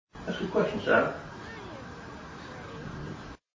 This next EVP is from The Merchant’s House Museum here in NYC.
The second clip is boosted to make it easier to hear without headphone.  Again, this second clip has been modified.
mhm_afraid-3db.mp3